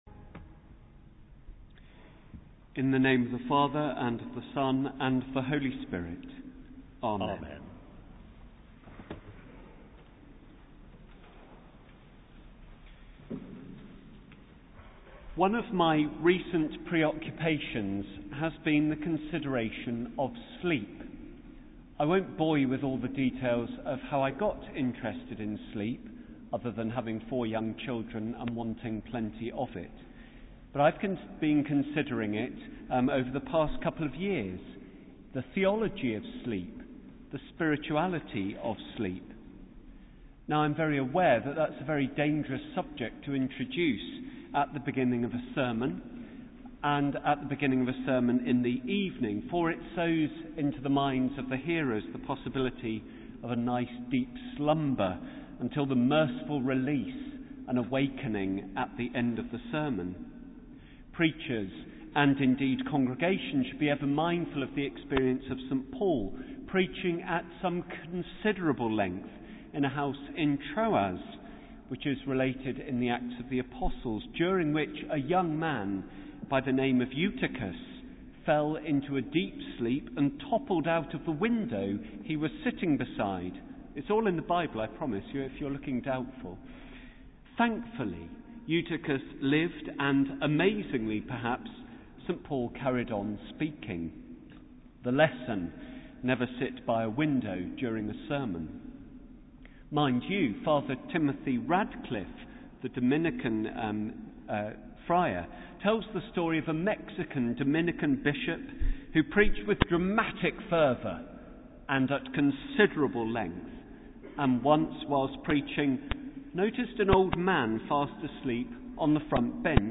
Sermon: Choral Evensong - 15 February 2015